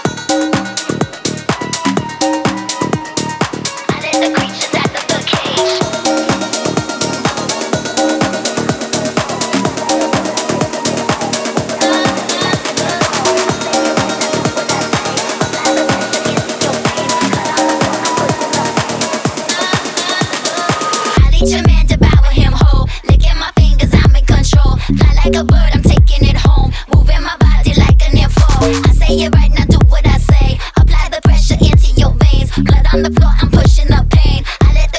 Жанр: Танцевальная музыка / Украинские
# Dance